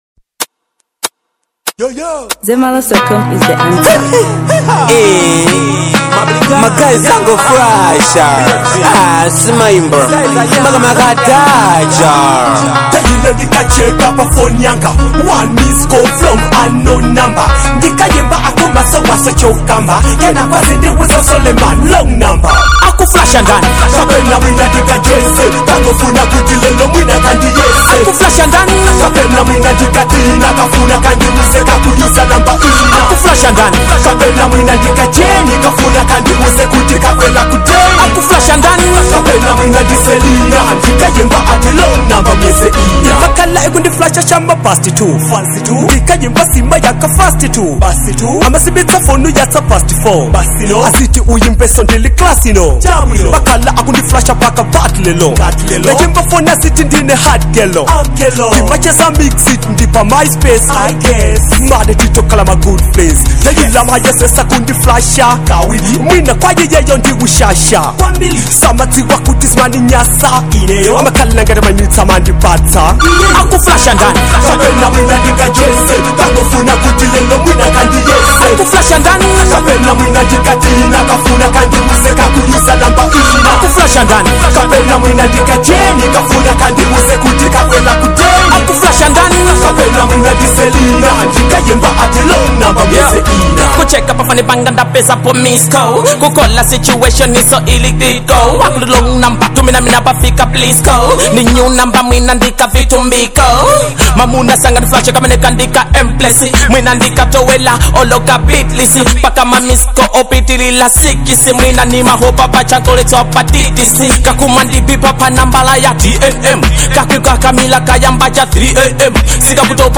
Dancehall • 2025-09-15